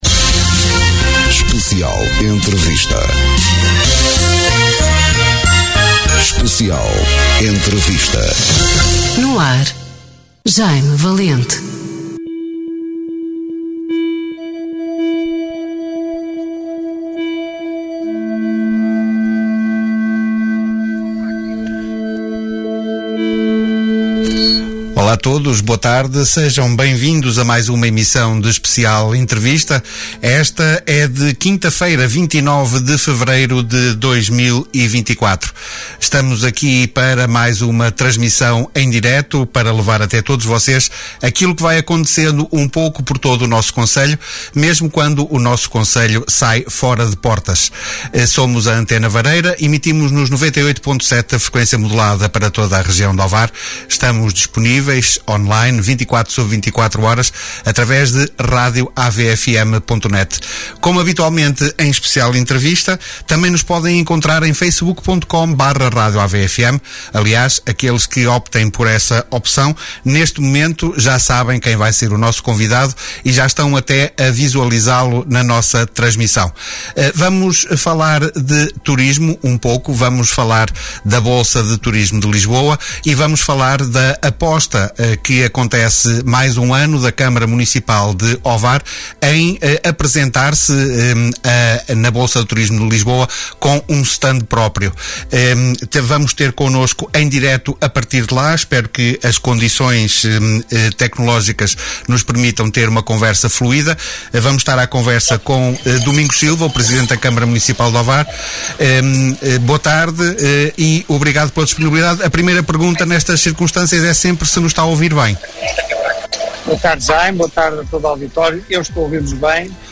Emissão: 29 de Fevereiro 2024 Convidado(s): Presidente Domingos Silva Descrição: A Câmara Municipal de Ovar aposta uma vez mais na participação na BTL Bolsa de Turismo de Lisboa. Vamos estar à conversa, em direto a partir de lá, com o Presidente Domingos Silva, que nos vai falar sobre os objetivos para 2024/25.
Especial Entrevista
Direitos reservados Especial Entrevista Conversas olhos nos olhos em direto Mais informações